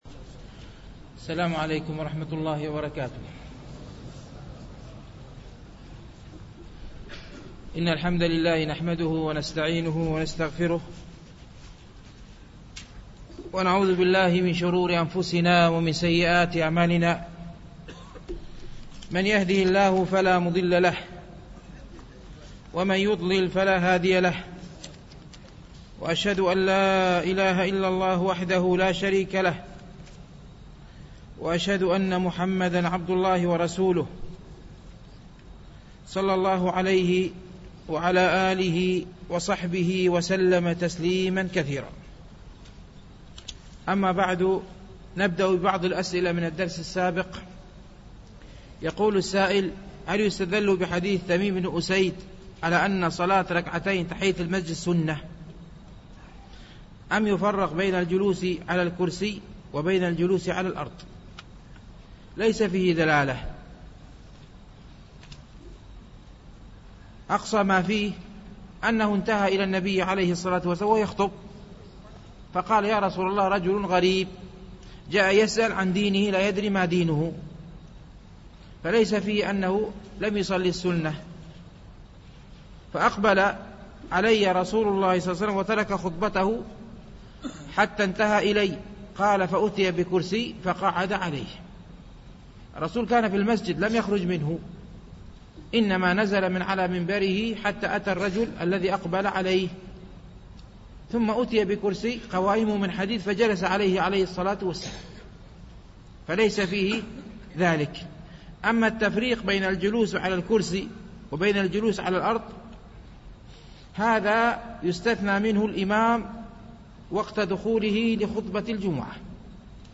شرح رياض الصالحين - الدرس السابع والستون بعد المئة